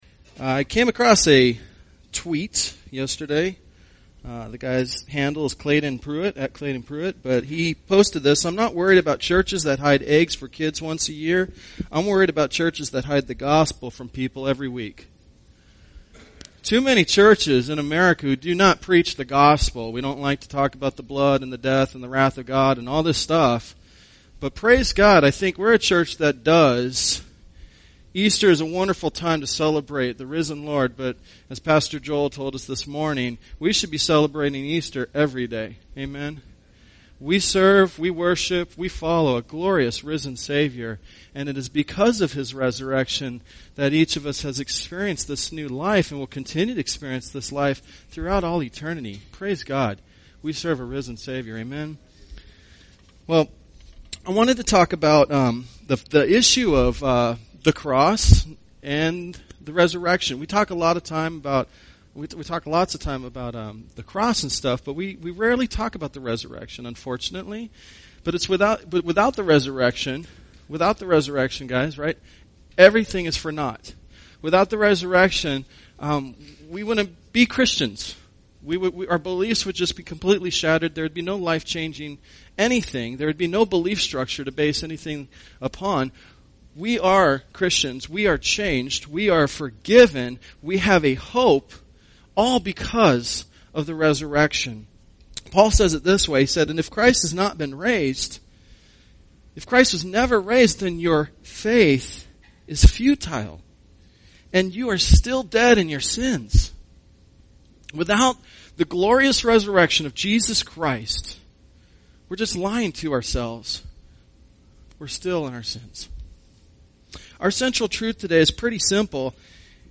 Easter Service: Christ the Victor